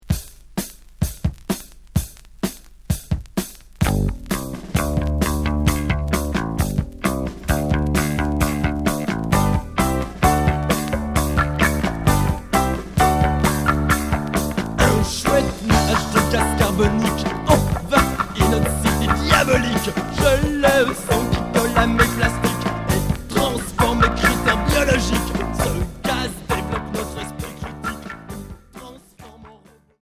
Post punk